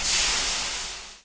fizz.ogg